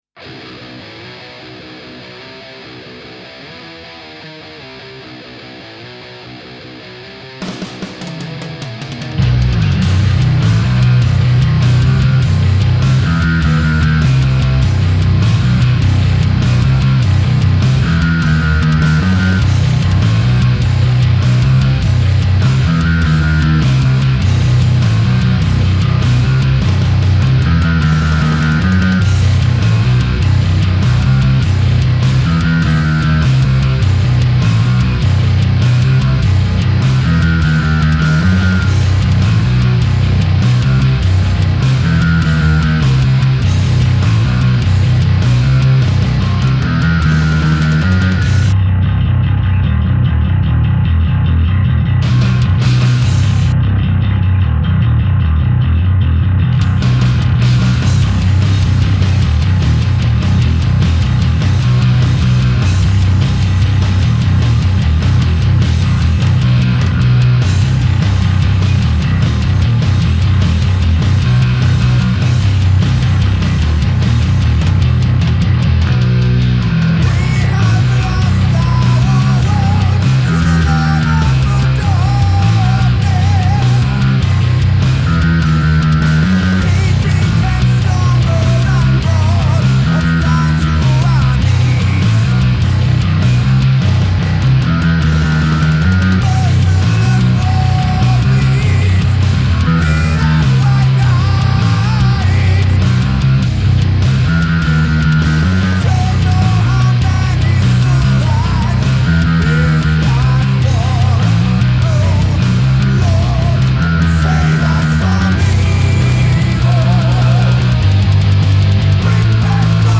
Im Gegenteil , mein LX klingt in den Hochmitten präsenter als die anderen Aber tatsächlich klingt die HAZ mit den EMGX offener …. beide Charakteristiken ergänzen sich aber sehr...
Hier mal der gleiche Song von gestern mit einem frisch eingetroffen Euro LX5 Bolt on.
Balance war ein bisschen zum Neck gedreht und Bass und Treble fast offen.
Bei dem speziellen Modell ist es auch so, dass die A-Saite für mich sehr stark heraussticht.